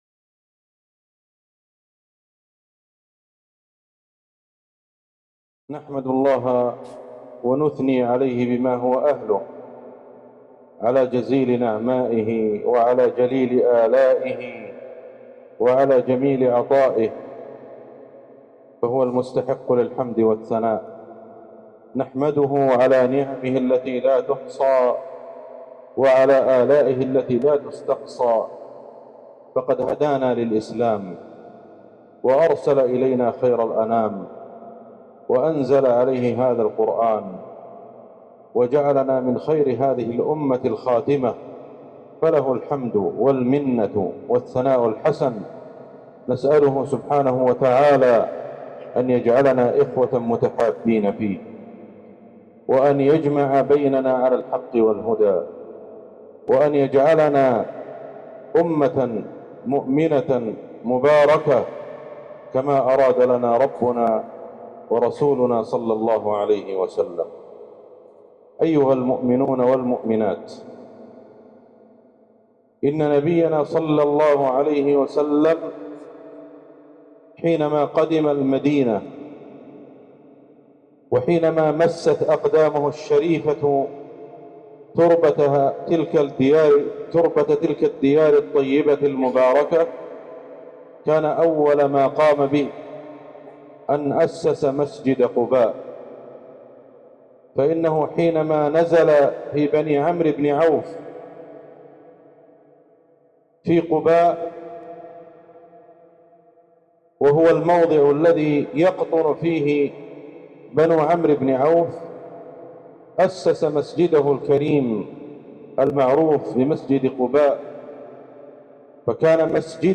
محاضرة إمام الحرم النبوي في إندونيسيا | في المسجد الكبير سوندا كلابا جاكرتا ٧-٤-١٤٤٦ هـ > زيارة الشيخ أحمد الحذيفي لجمهورية إندونيسيا > المزيد - تلاوات الشيخ أحمد الحذيفي